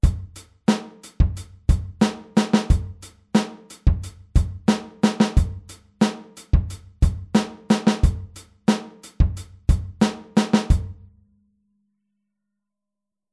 Anders als die Wechselschläge bei 16tel spielt die rechte Hand durchgängig alle 8tel.
Groove02a-8tel.mp3